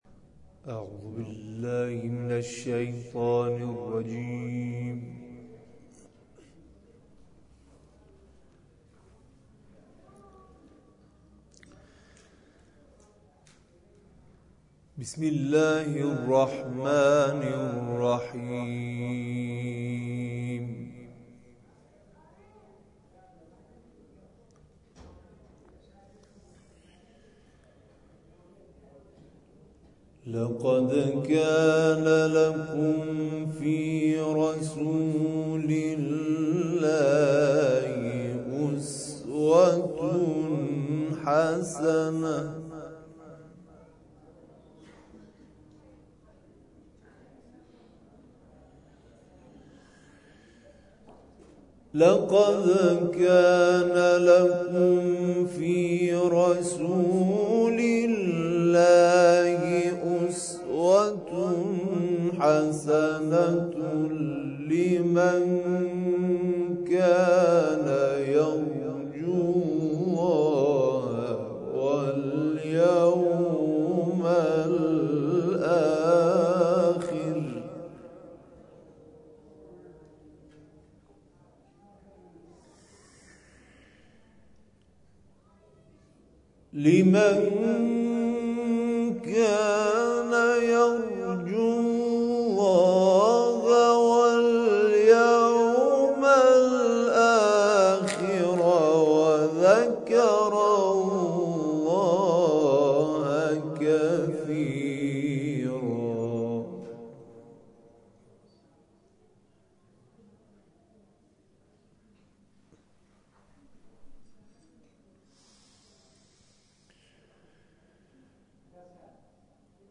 نفحات‌القرآن سلسله کرسی‌های تلاوتی است که به همت مؤسسه‌ای با همین نام برای گسترش فرهنگ استماع تلاوت قرآن و تحقق تأکید رهبر معظم انقلاب در این خصوص دایر شده است و تمامی قاریان آن به صورت داوطلبانه و بدون دریافت هدیه در این کرسی‌ها تلاوت می‌کنند.